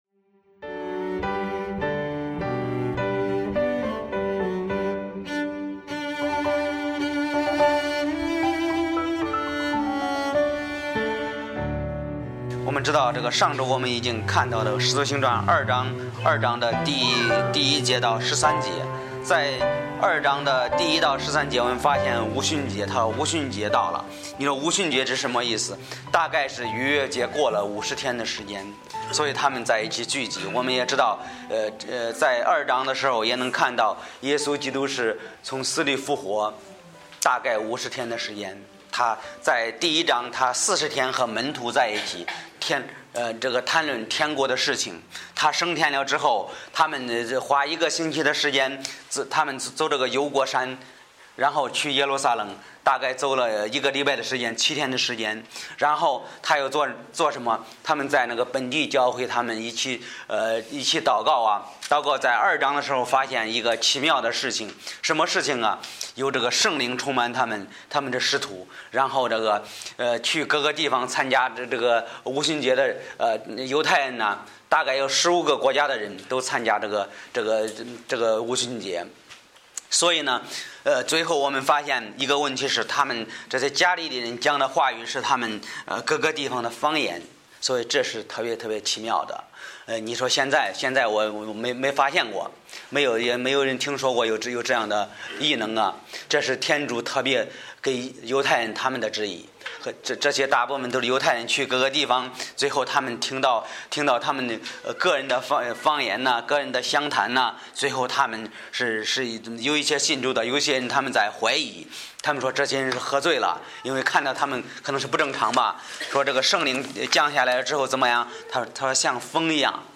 Bible Text: 使徒行传2：14-24 | 讲道者